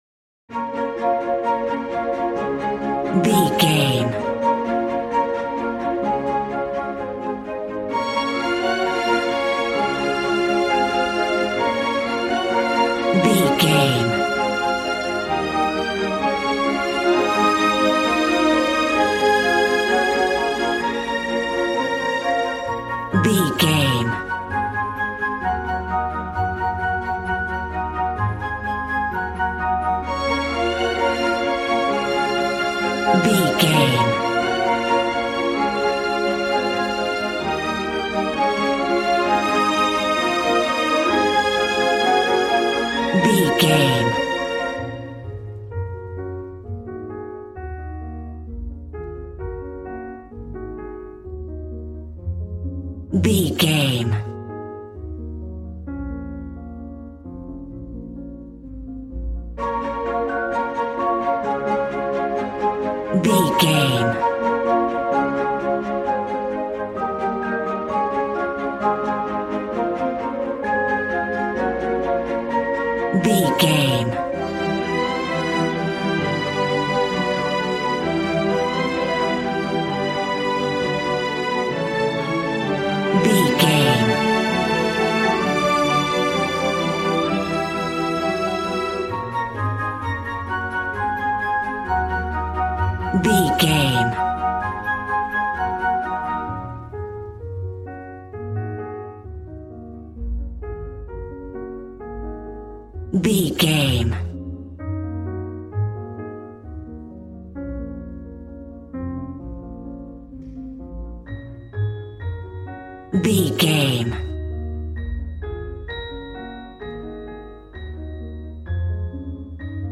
Regal and romantic, a classy piece of classical music.
Aeolian/Minor
B♭
regal
strings
violin